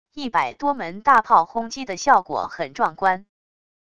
一百多门大炮轰击的效果很壮观wav音频